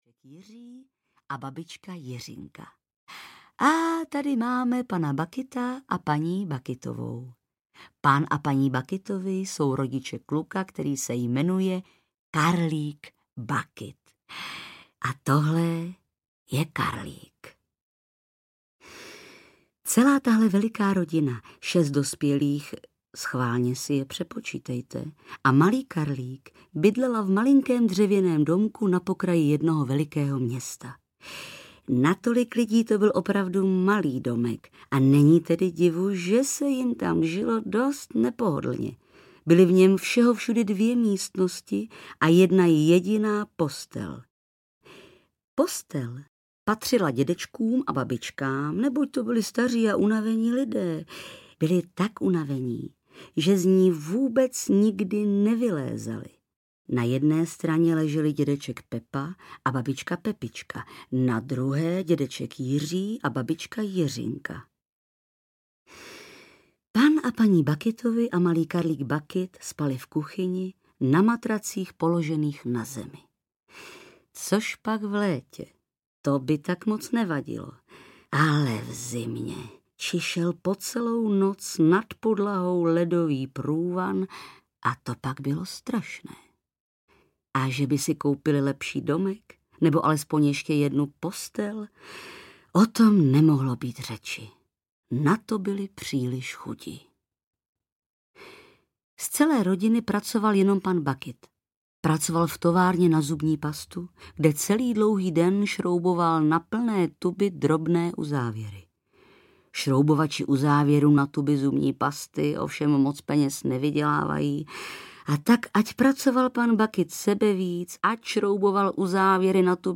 Karlík a továrna na čokoládu audiokniha
Ukázka z knihy
• InterpretBarbora Hrzánová